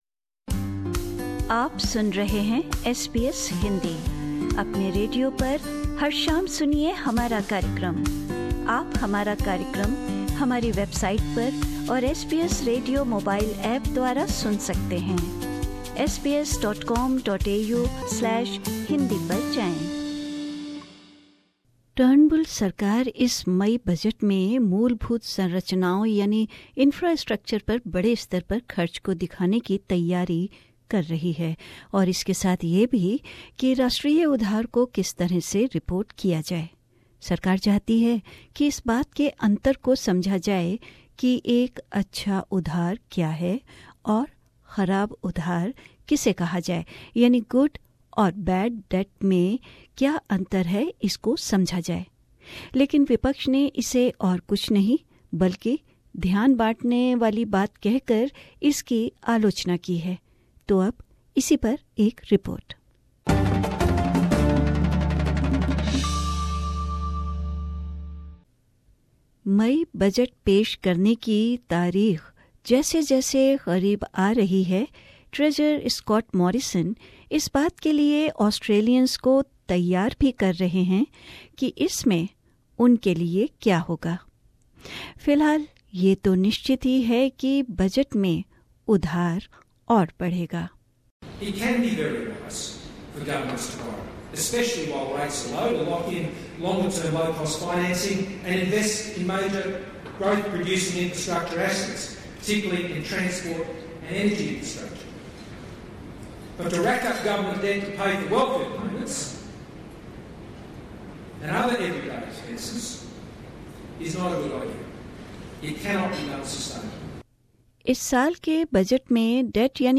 Scott Morrison giving a pre-budget address in Sydney Source: AAP